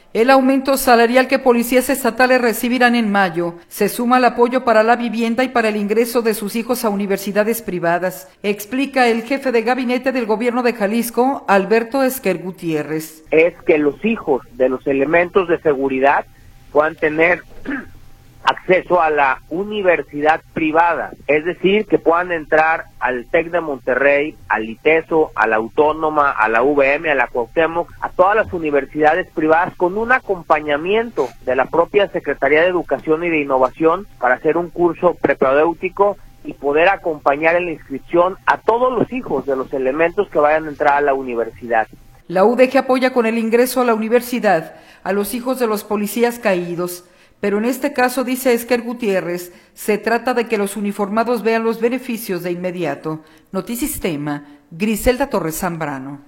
Al aumento salarial que policías estatales recibirán en mayo, se suma el apoyo para la vivienda y para el ingreso de sus hijos a universidades privadas, explica el Jefe de Gabinete del Gobierno de Jalisco, Alberto Esquer Gutiérrez.